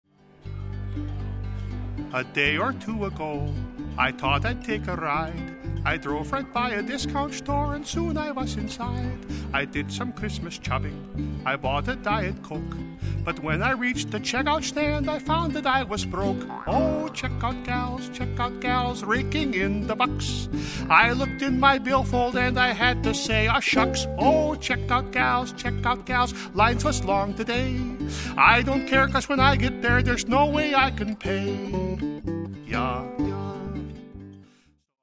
well-recorded and hilarious.